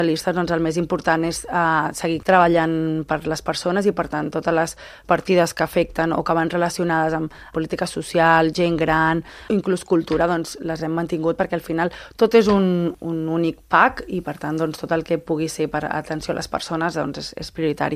Rando ha explicat que és una proposta pressupostària “continuista” que respon al pacte entre partits, i que novament s’ha fet un exercici de revisió per ajustar partides i que sigui el màxim de realista. La portaveu socialista ha dit que tenen la seva empremta.